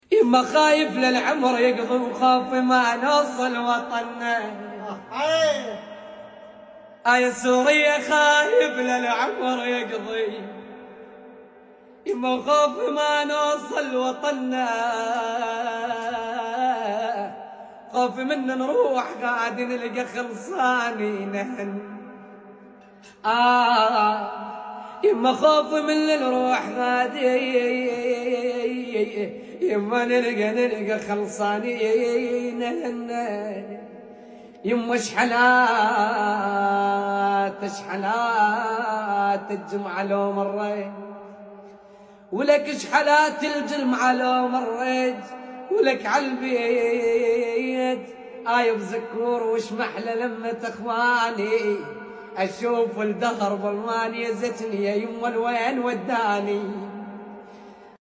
صوته حلو بچاني والله 😢
صوت رائع جدا